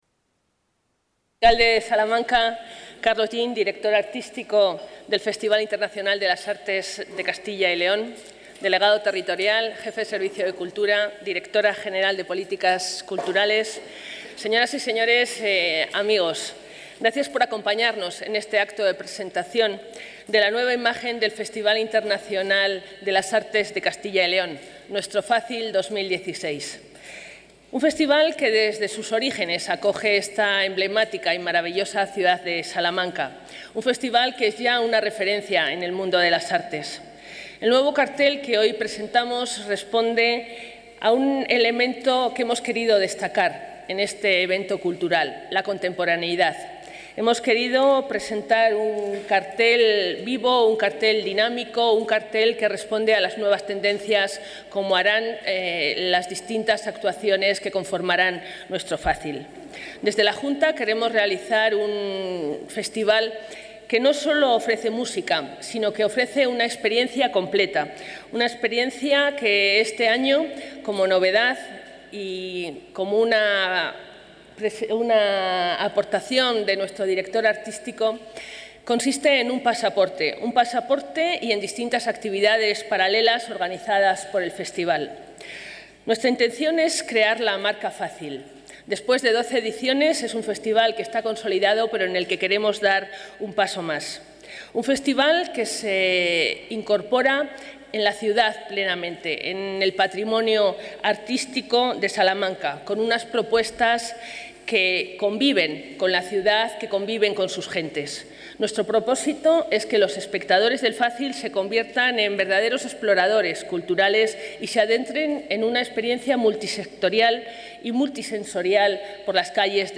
Audio Intervencion Josefa Garcia Cirac presentacion cartel FACYL.